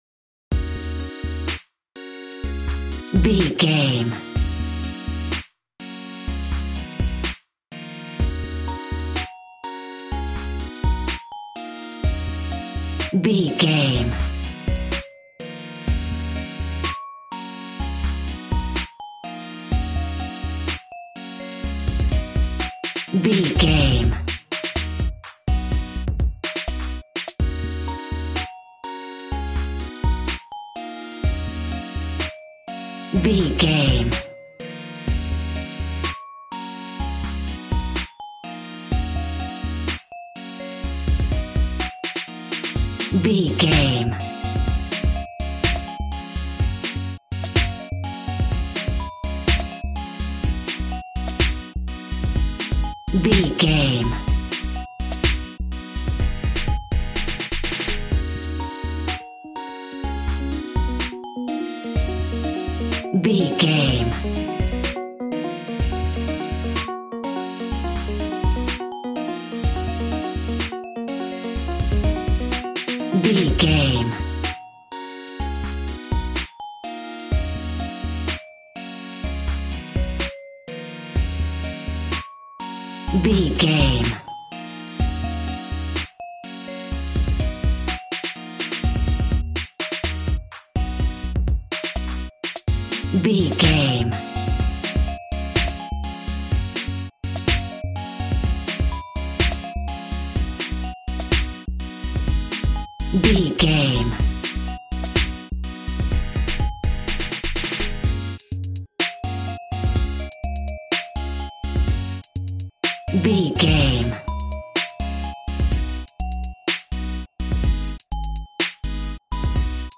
Aeolian/Minor
E♭
groovy
synthesiser
drums
cool
piano